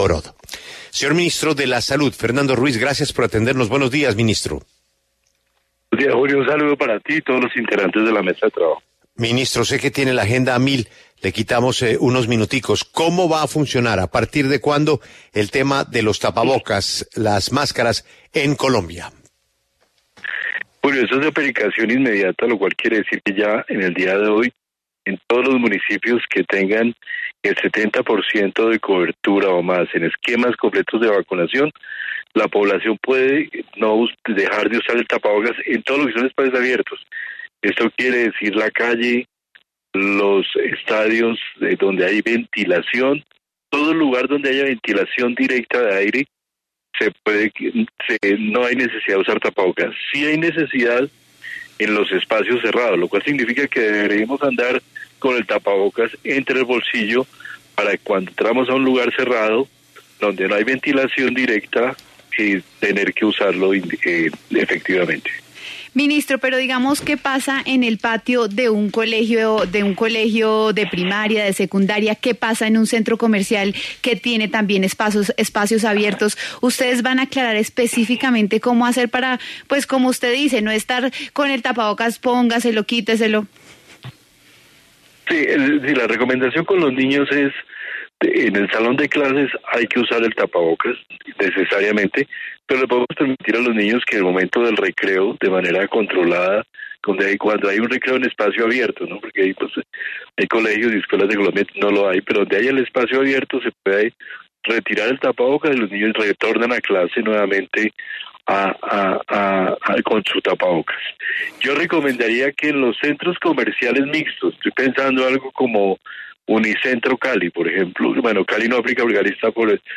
En diálogo con La W, el ministro de Salud, Fernando Ruiz, se refirió al comportamiento de la pandemia del COVID-19 en el país.